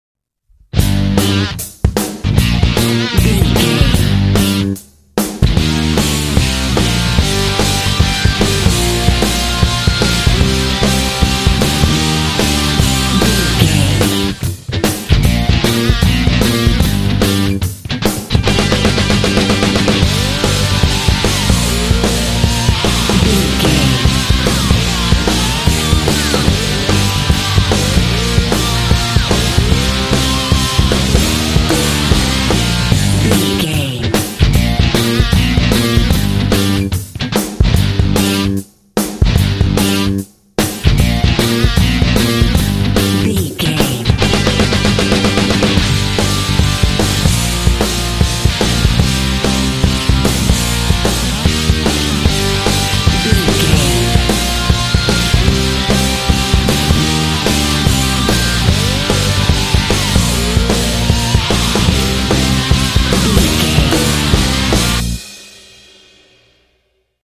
Epic / Action
Fast paced
Aeolian/Minor
powerful
energetic
heavy
drums
electric guitar
bass guitar
rock
heavy metal
classic rock